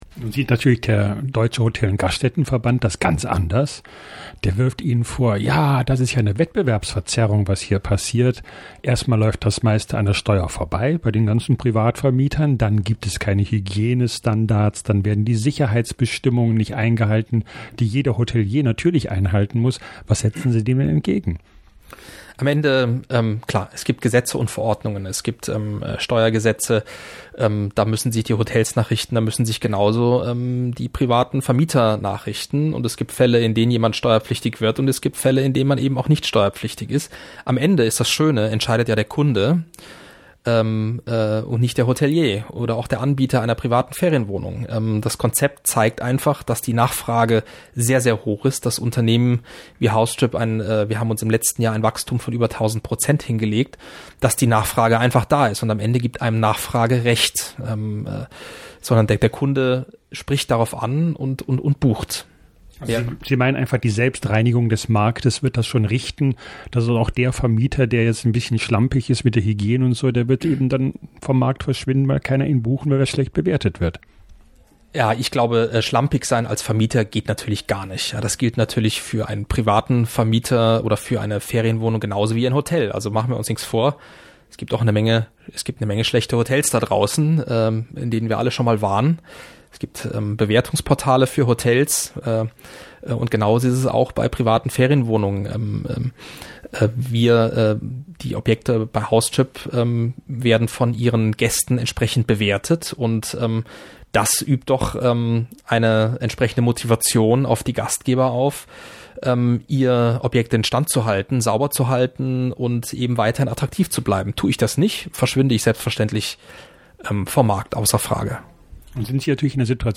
Direktlink: Gespräch